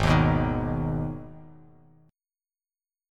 Listen to Abm strummed